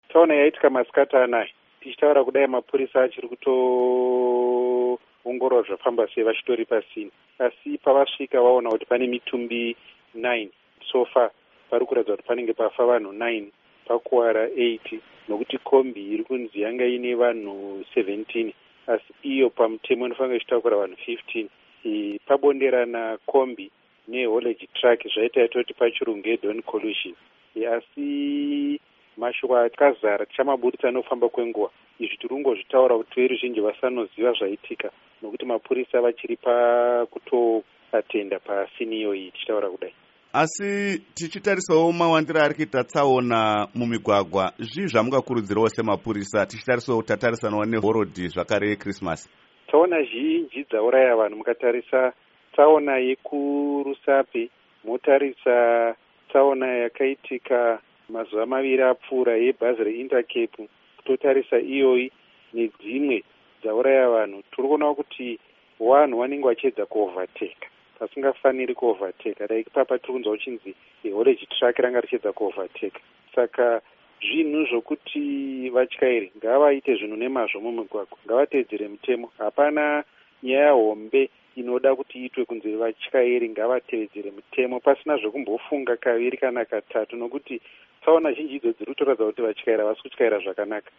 Hurukuro naAssistant Commissioner Paul Nyathi